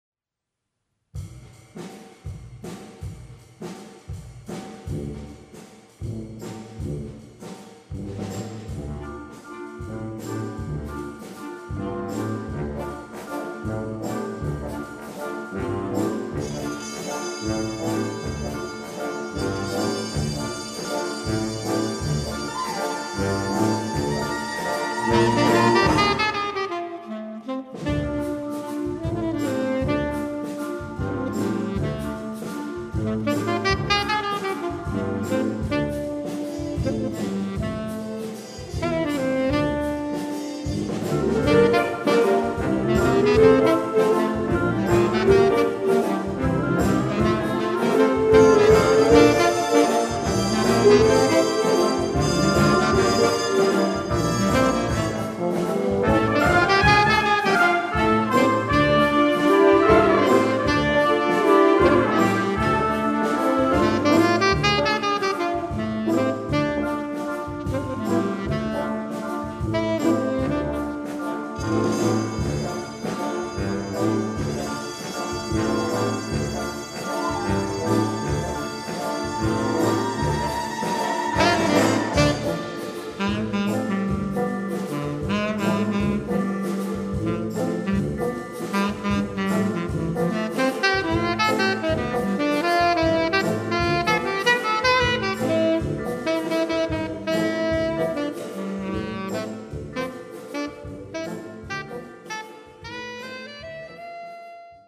Für Tenorsax Solo mit BLO
Besetzung: Blasorchester